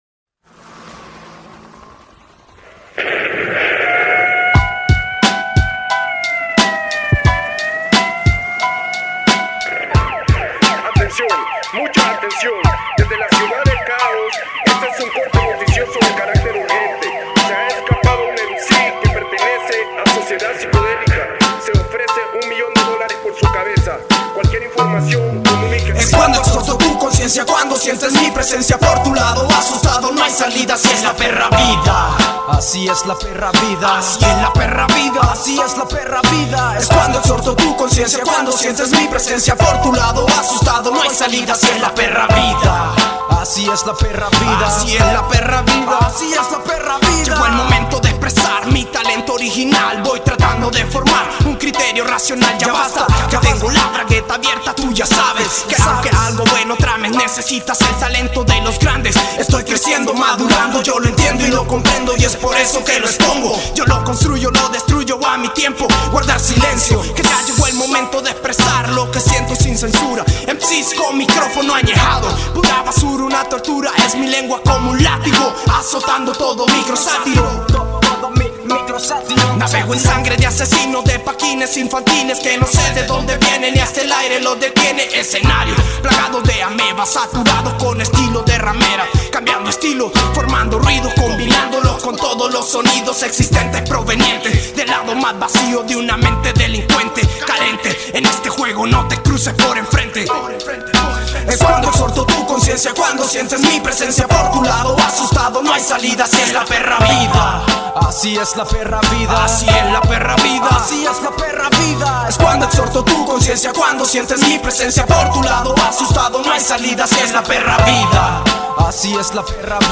Voces urbanas